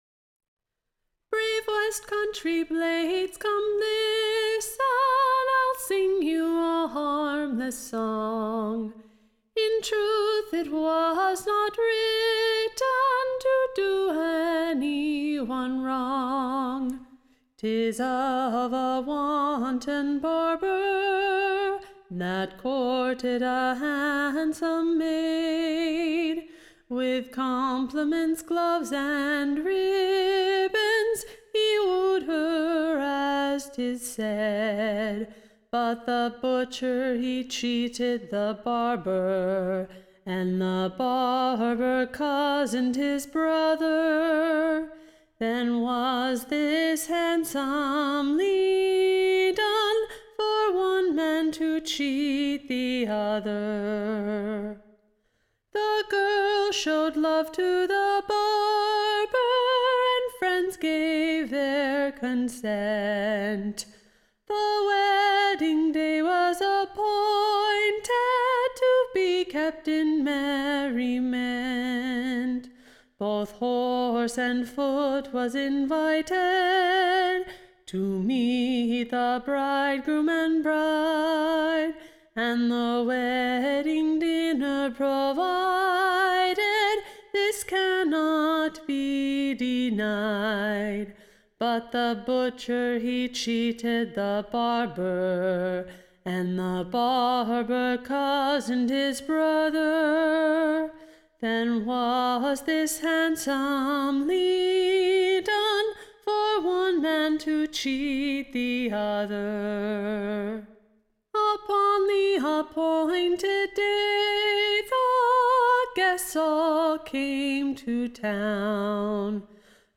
Recording Information Ballad Title The VVest-Countrey Cheat upon Cheat; / OR, / No Jest like a True Jest.